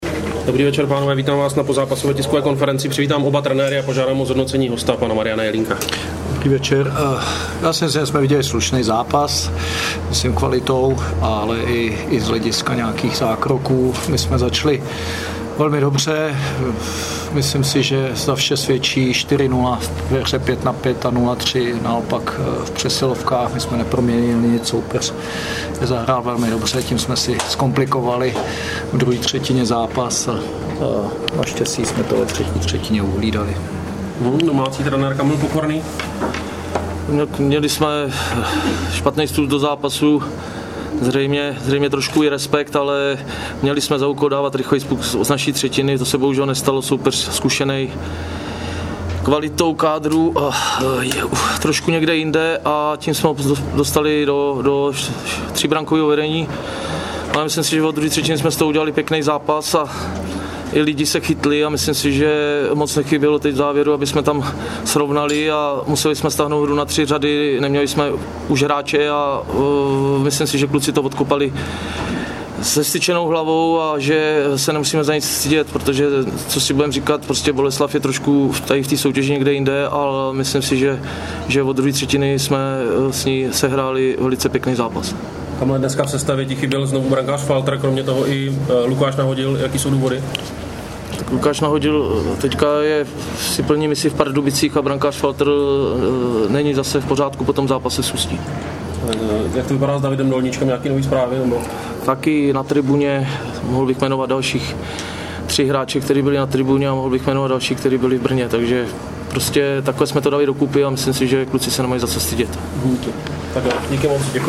Tiskovka po utkání Třebíč - Ml. Boleslav 3:4
Tiskovka_po_utkani_T_719.MP3